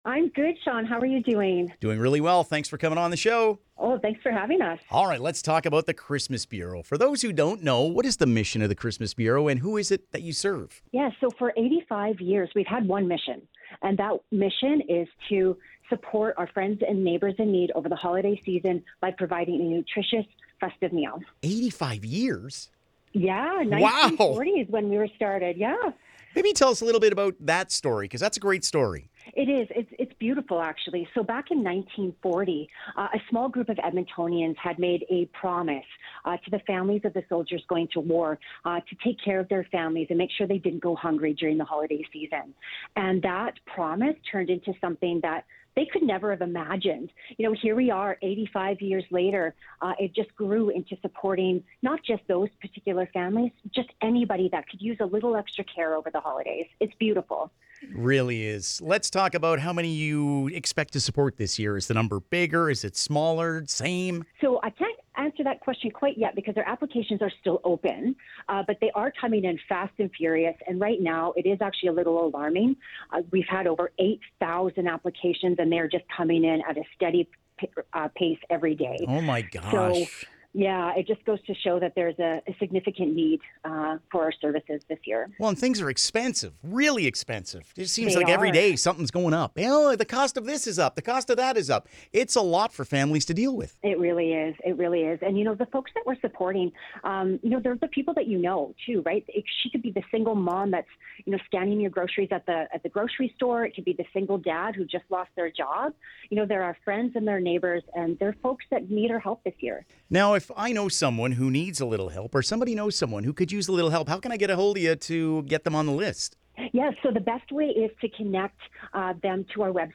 christmas-bureau-int.mp3